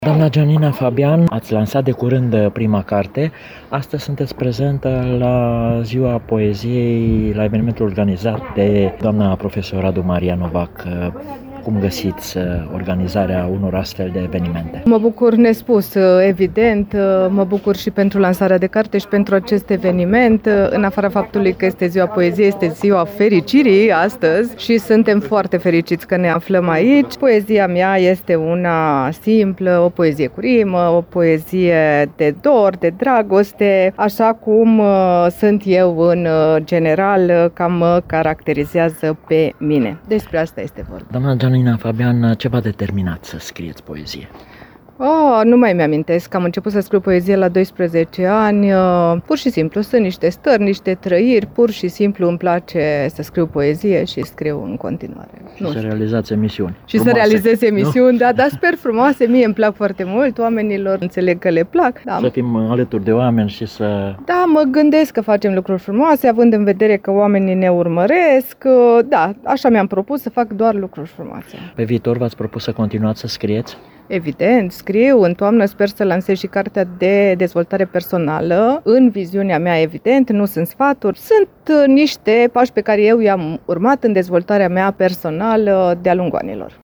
Am stat de vorbă cu unii dintre ei iar pe alții i-am ascultat recitând.